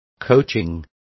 Also find out how entrenamiento is pronounced correctly.